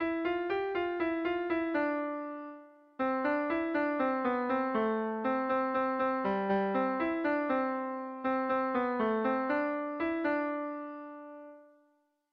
Air de bertsos - Voir fiche   Pour savoir plus sur cette section
Kopla handia
ABD